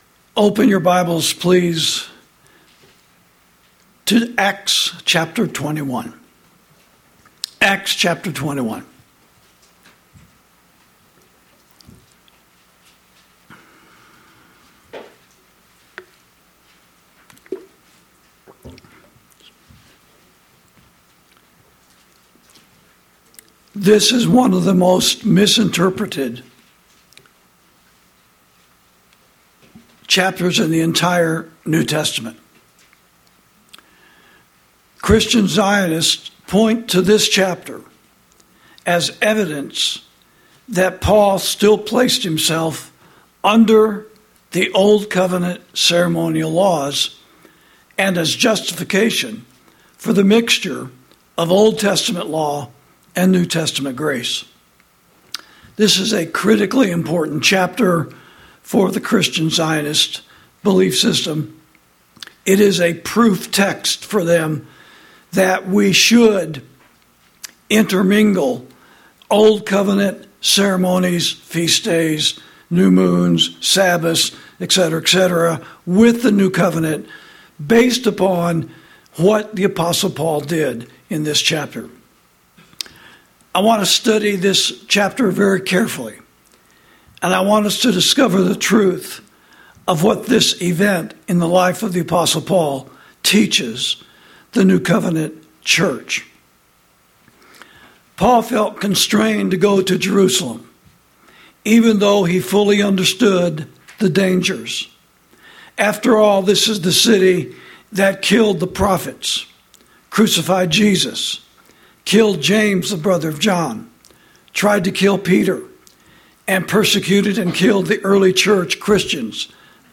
Sermons > Paul's Compromise With The Jerusalem Jews: What Are The Lessons?